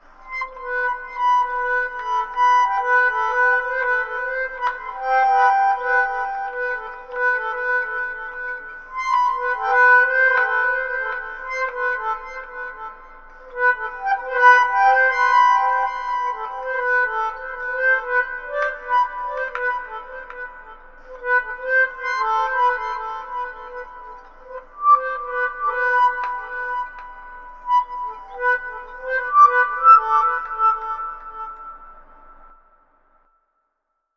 描述：葡萄牙语文本，语音。
声道立体声